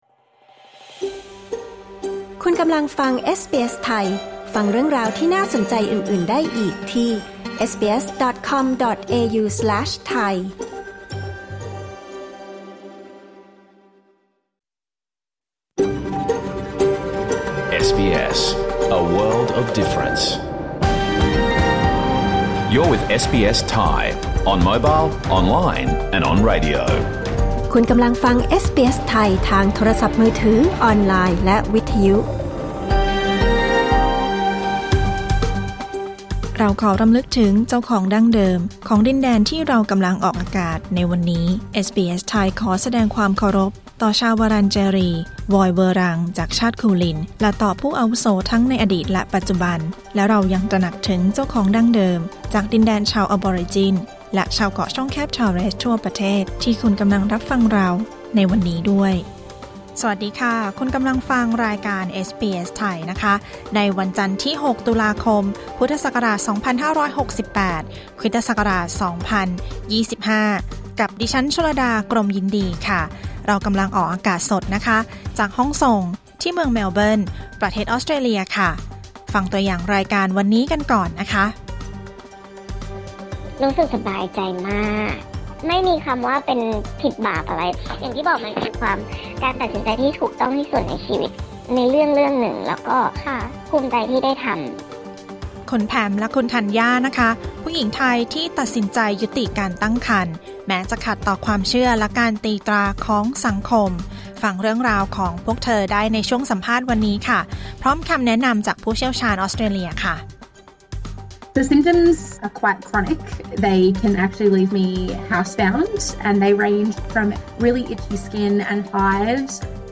รายการสด 6 ตุลาคม 2568